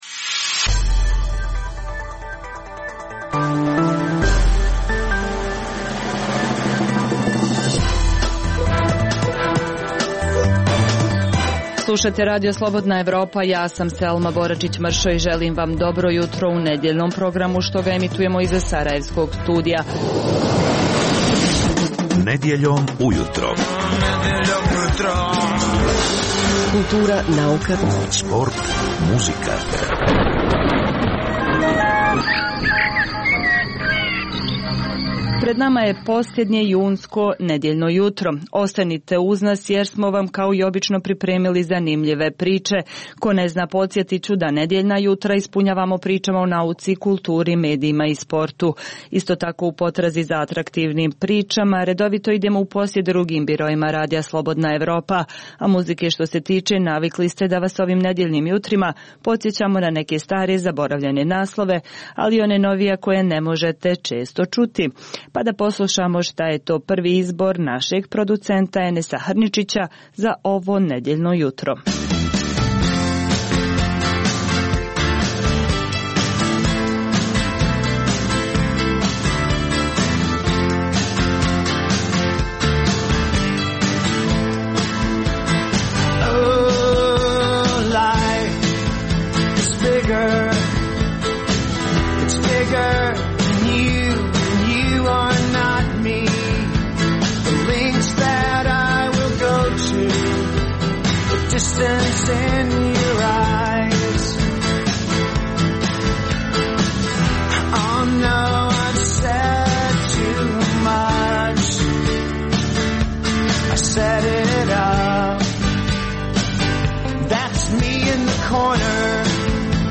Nedjeljni jutarnji program za Bosnu i Hercegovinu. Poslušajte intervju sa zanimljivim gostom i, uz vijesti i muziku, pregled novosti iz nauke i tehnike, te čujte šta su nam pripremili novinari RSE iz regiona.